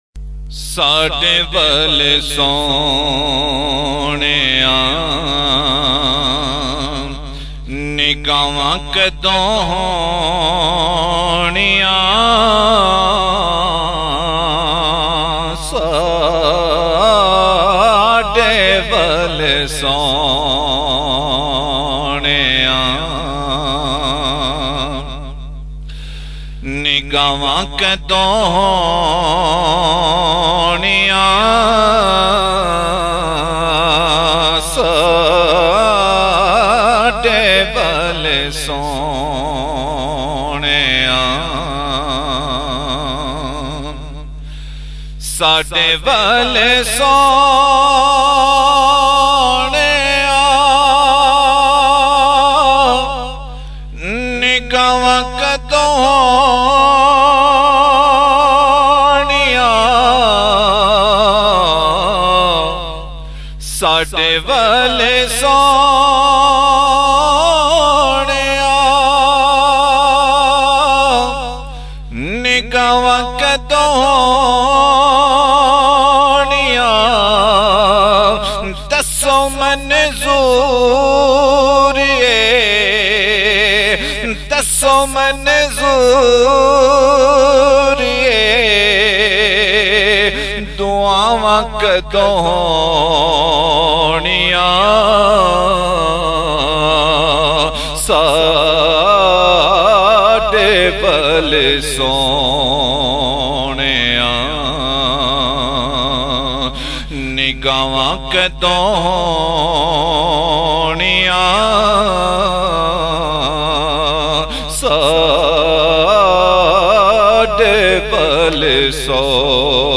punjabi naat